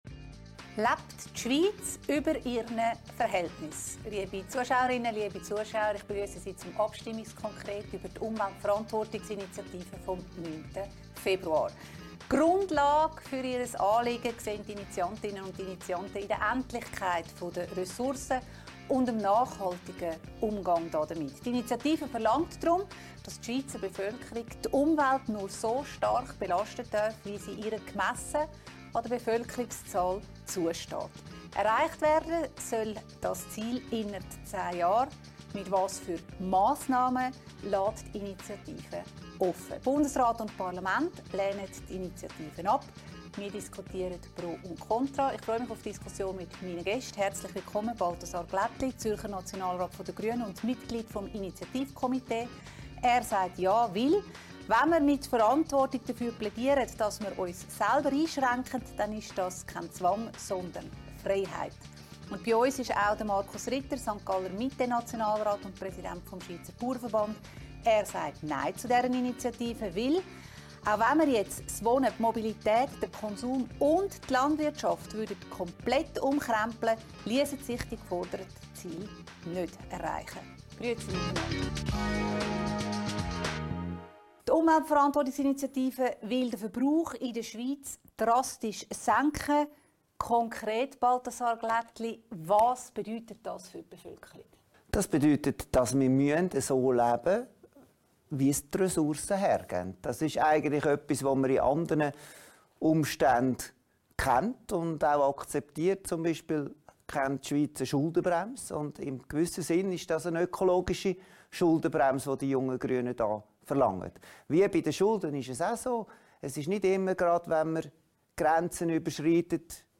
diskutiert mit den beiden Nationalräten Balthasar Glättli, Grüne/ZH und Bauernpräsident Markus Ritter Die Mitte/SG, PRO und KONTRA der Umweltverantwortungsinitiative, die am 9. Februar zur Abstimmung kommt.